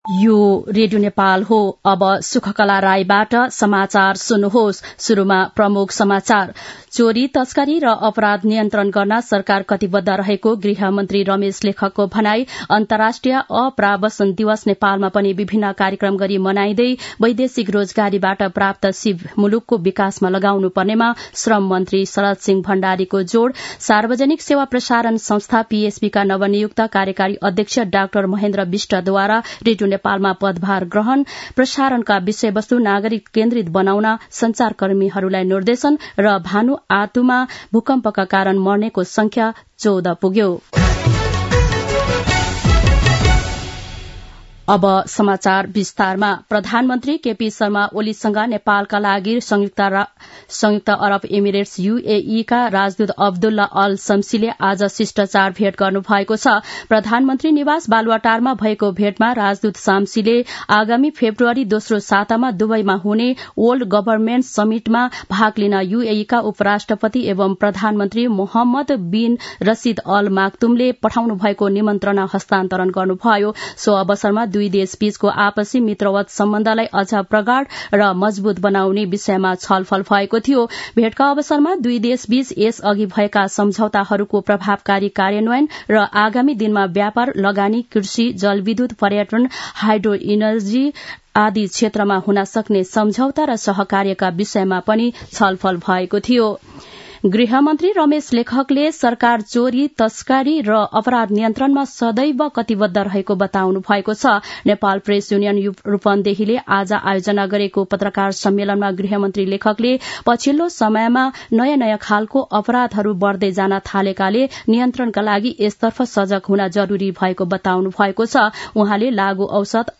दिउँसो ३ बजेको नेपाली समाचार : ४ पुष , २०८१